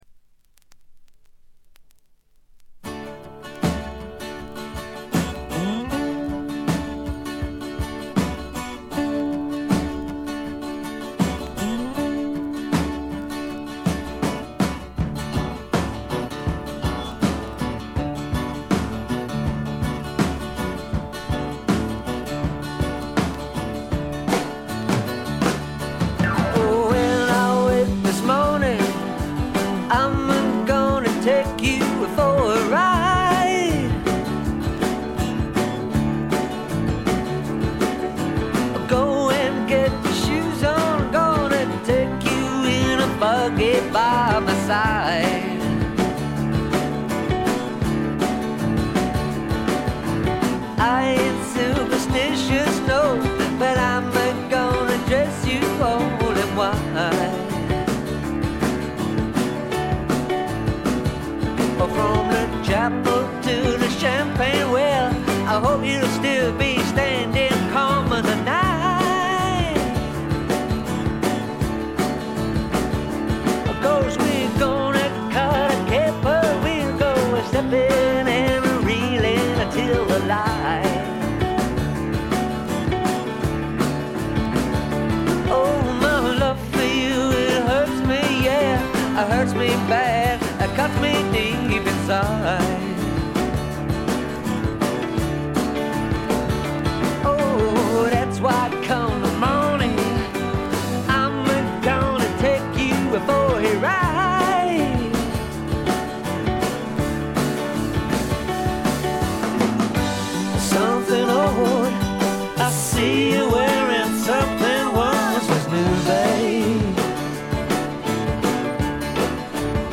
試聴曲は現品からの取り込み音源です。
guitar, mandolin, fiddle, keyboards, harmonica, vocals
violin, keyboards, harp, whistle
drums